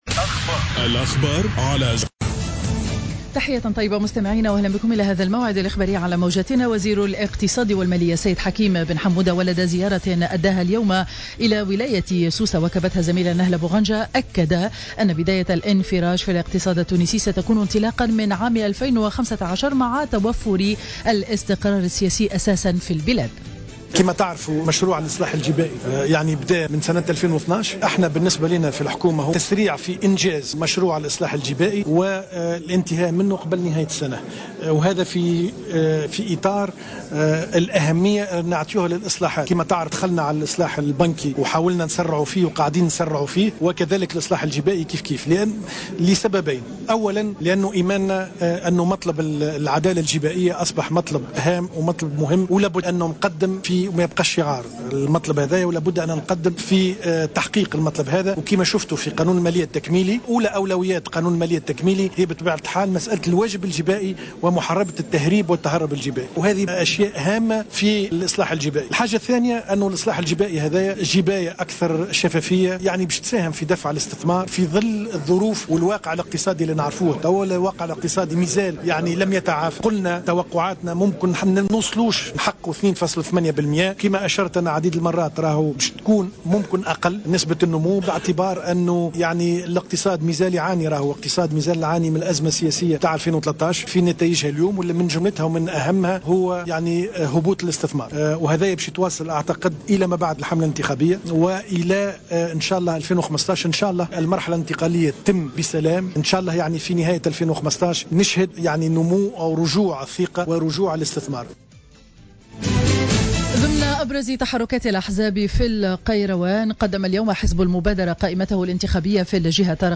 نشرة أخبار منتصف النهار ليوم الخميس 28-08-14